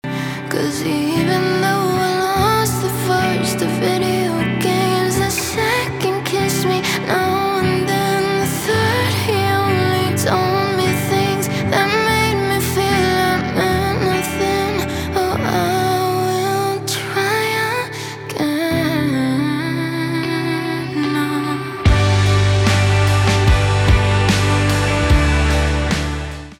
соул
гитара , барабаны , чувственные
грустные